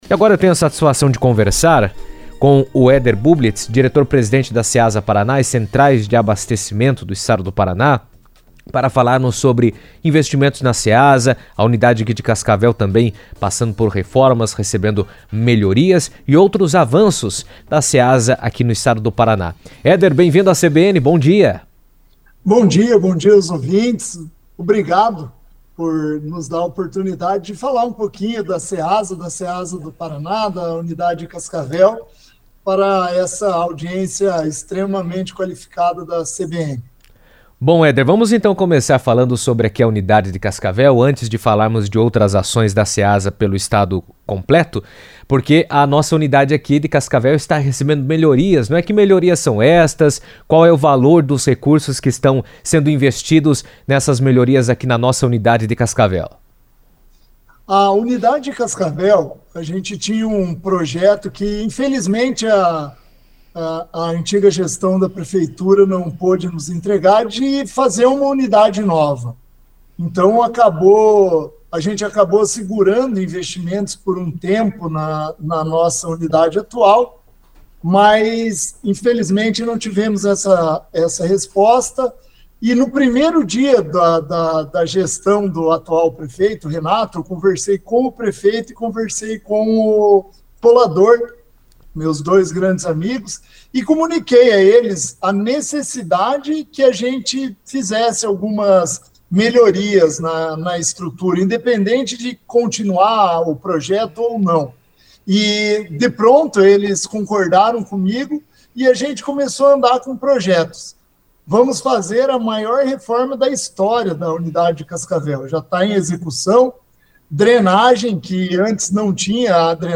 Em entrevista à CBN, o diretor-presidente da Ceasa Paraná, Éder Bublitz, destacou a importância das obras para fortalecer o espaço como ponto estratégico de abastecimento na região Oeste.